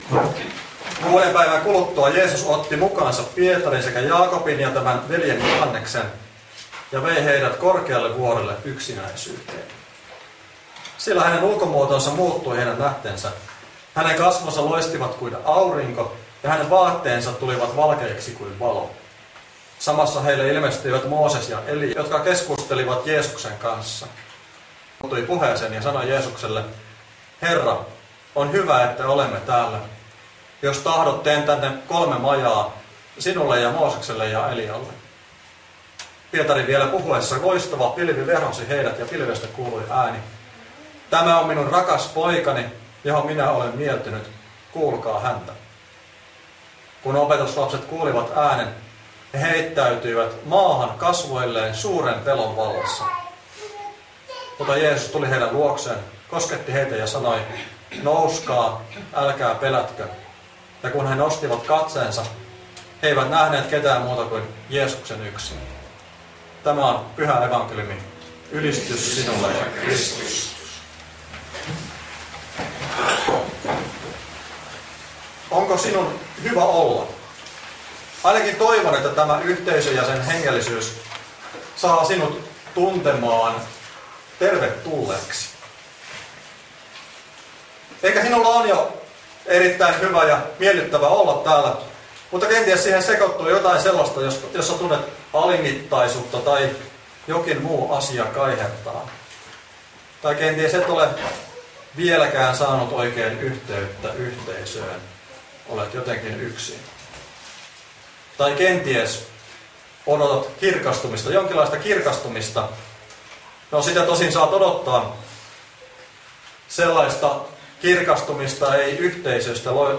Lappeenrannassa kirkastussunnuntaina Tekstinä Matt. 17:1–8